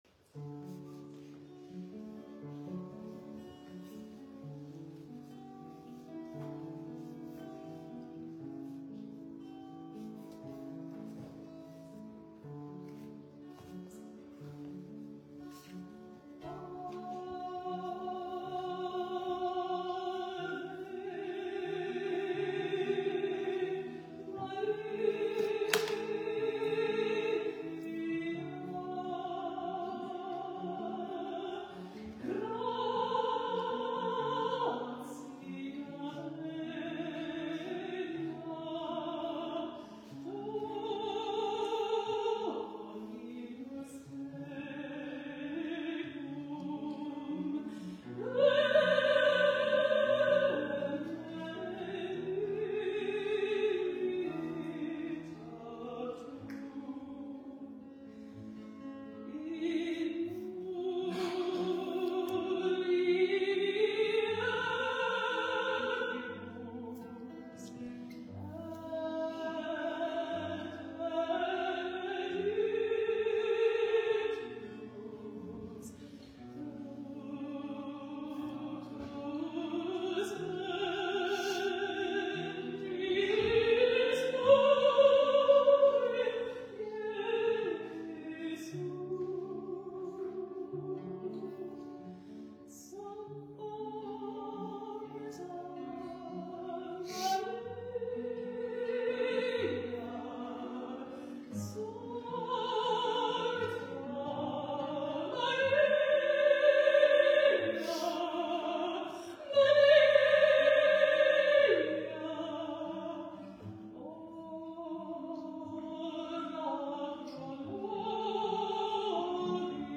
sang an operatic rendition
Ave Maria in honor of the late mayor
played the guitar as her powerful voice reverberated off of the cavernous walls of Old St. Joes Church.